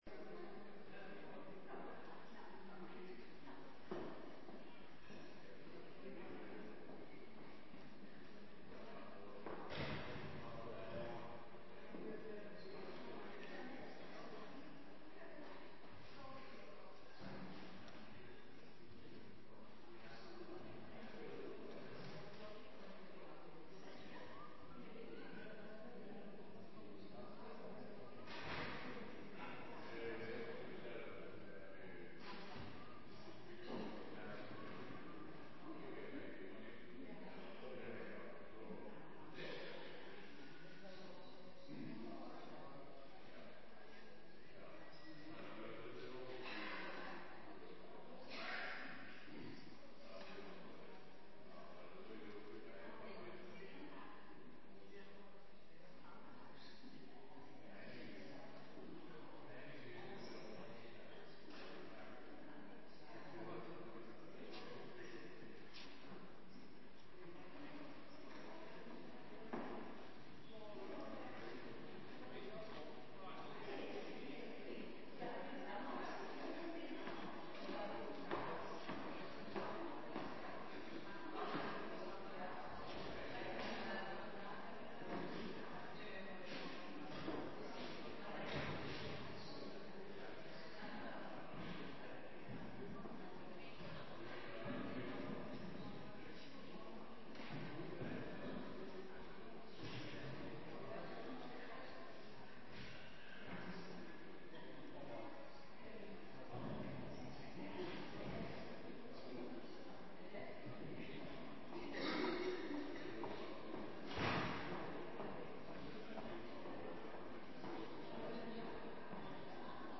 Kerkdienst meeluisteren - Ontmoetingskerk Heerde